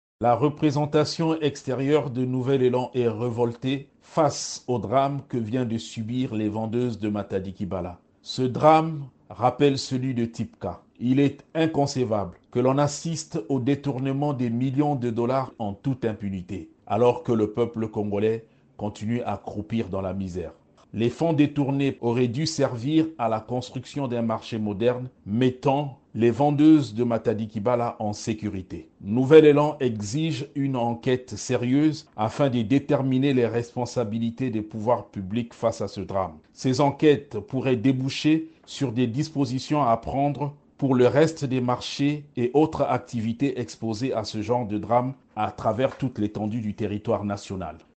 Intervenant sur Radio Okapi